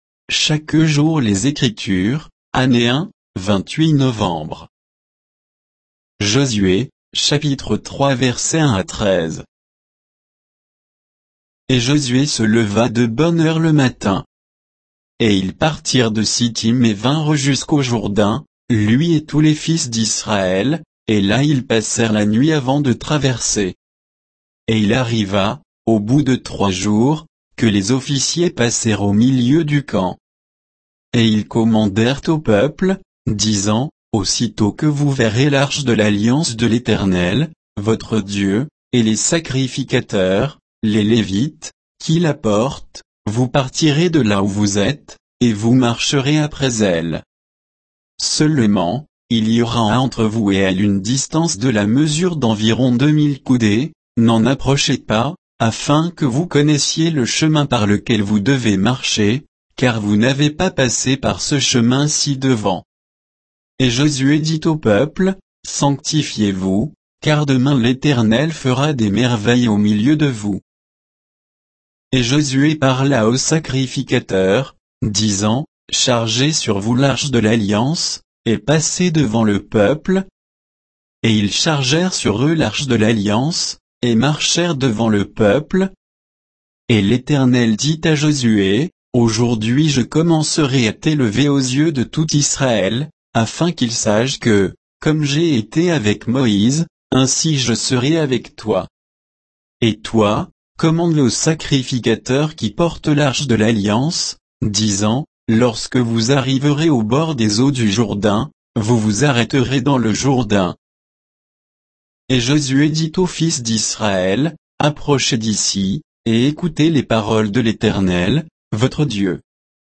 Méditation quoditienne de Chaque jour les Écritures sur Josué 3